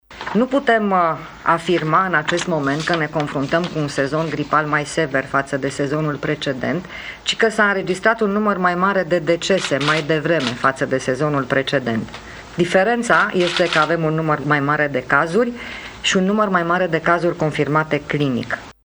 Ministrul Sănătății spune că situația nu este mai gravă ca anul trecut: